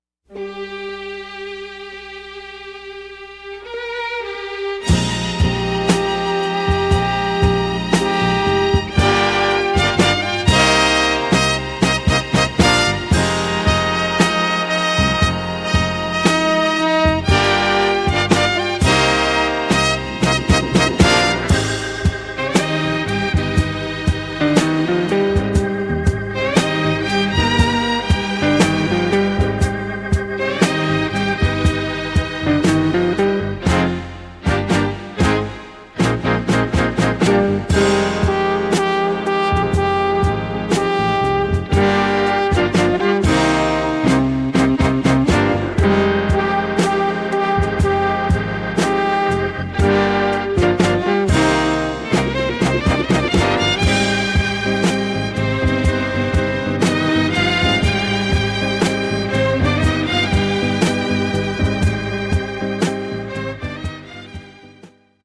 Mp3 demos of a few below.(Wav files will be better quality)